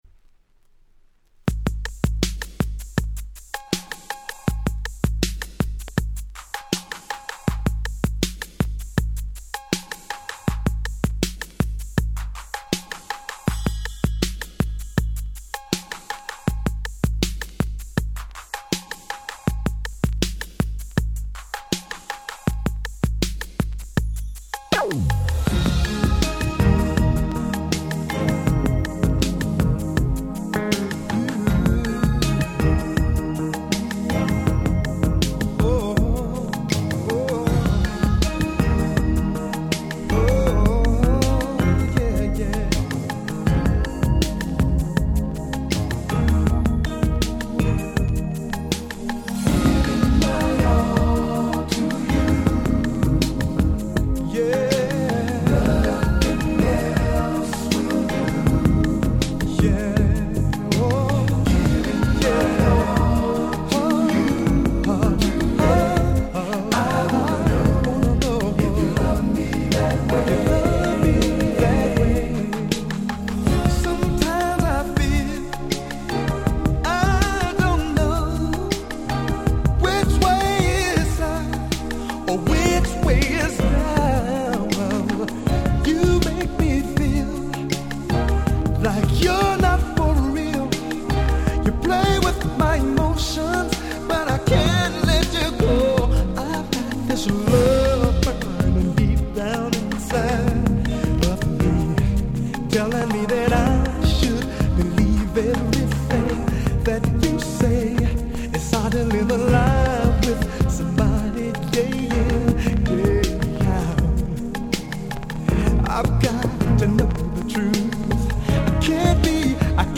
90' Super Hit R&B LP !!